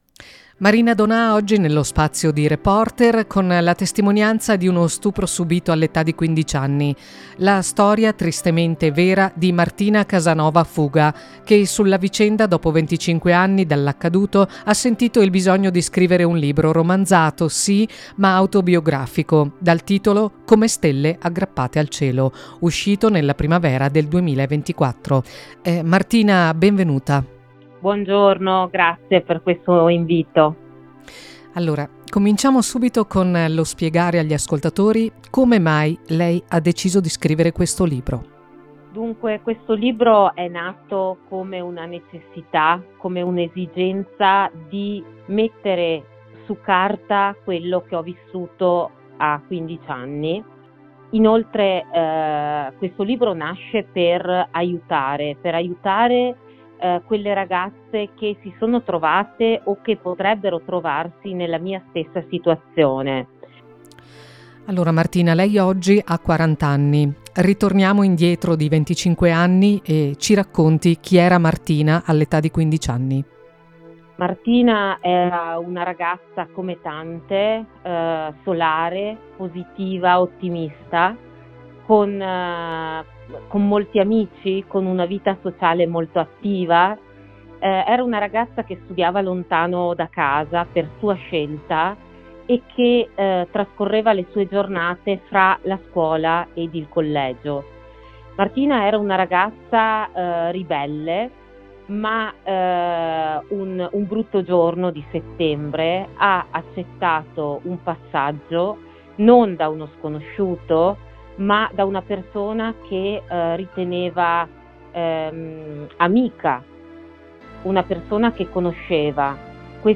L’INTERVISTA